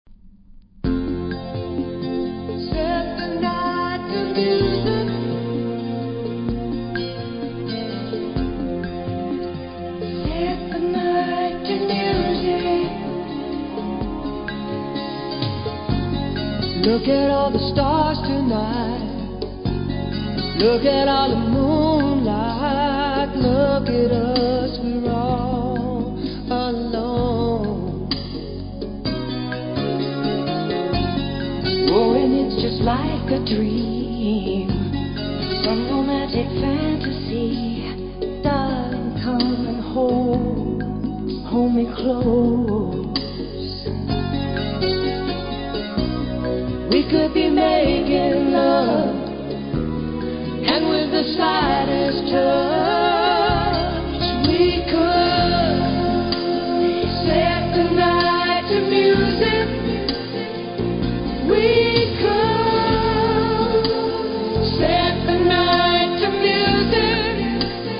80'S MALE GROUP